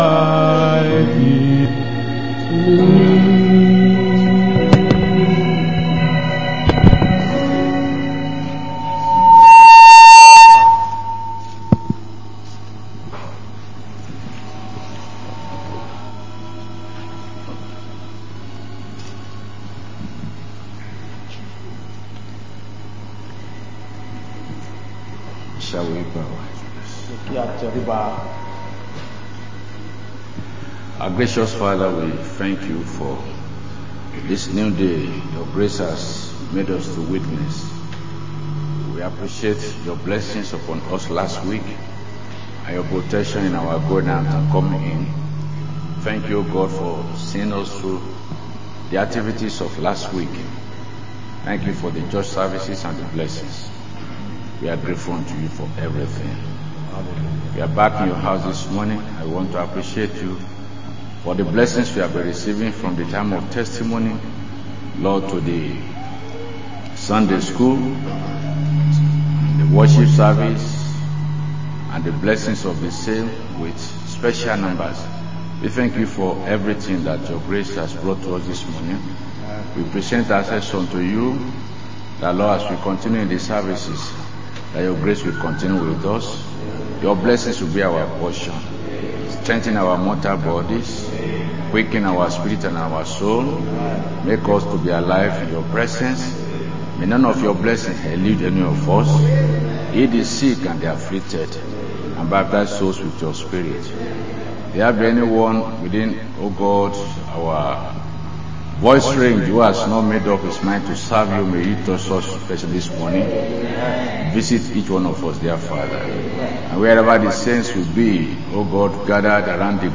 Sunday Main Service